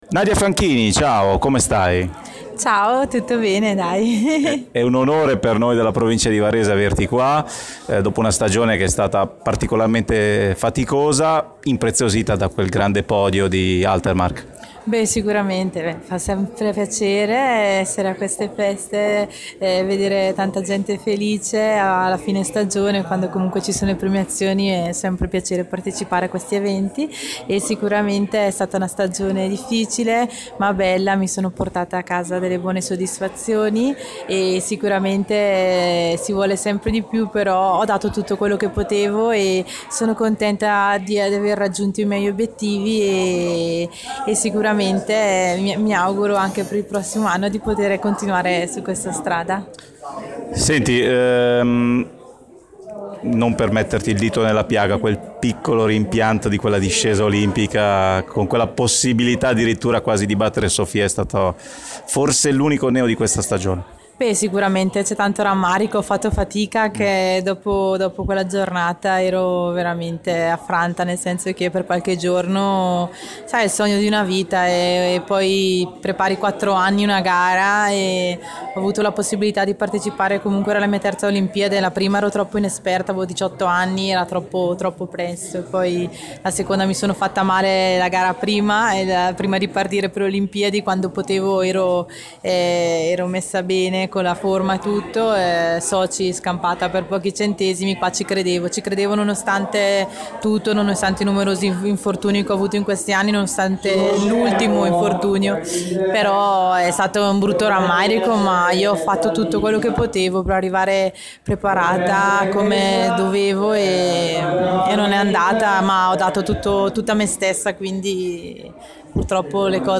Nadia Fanchini, campionessa di sci alpino, tre partecipazioni olimpiche, e due medaglie iridate vinte, un argento nella discesa libera a Schladming 2013 e un bronzo sempre in discesa a Val d’Isère 2009, è stata ospite lo scorso sabato a Somma Lombardo (Va) dello sci club A.S.S.I. in occasione della serata di chiusura della stagione sciistica, consegnando i premi agli atleti che hanno partecipato al Campionato Sommese.
A margine della serata, abbiamo intervistato Nadia Fanchini.
intervista-nadia-fanchini.mp3